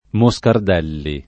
[ mo S kard $ lli ]